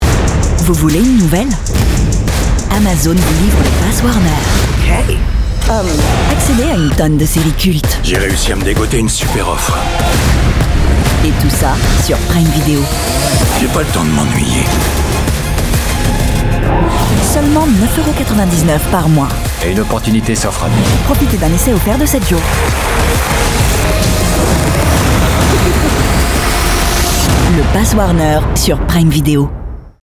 Français - Pass Warner (naturel)